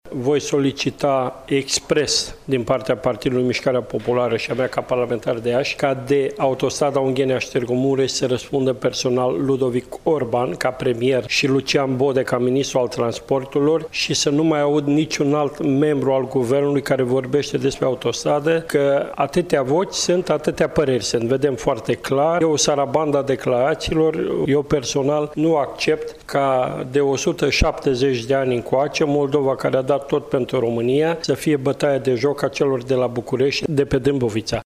În același context, deputatul PMP de Iași, Petru Movilă a declarat într-o conferință de presă că va cere ca proiectul autostrăzii A8 să treacă în răspunderea premierului Ludovic Orban şi a ministrului Transporturilor, Lucian Bode.